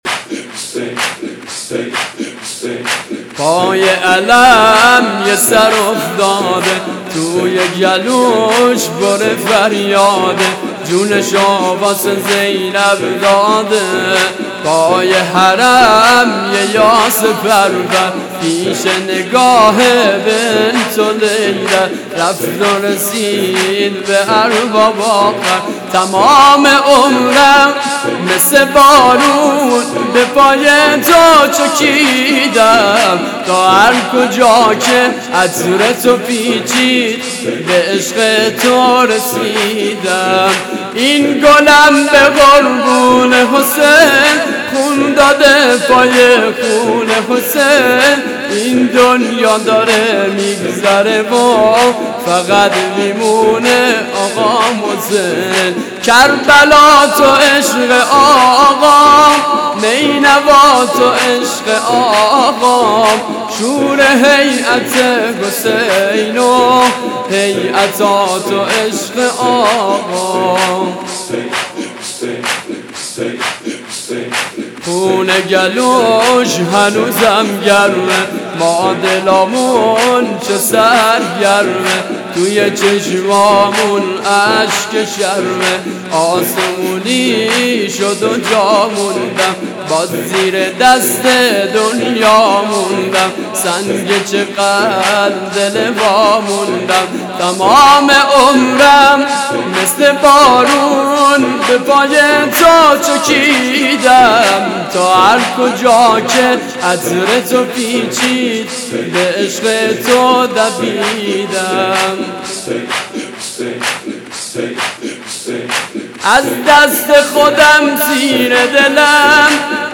نوحه محرم 97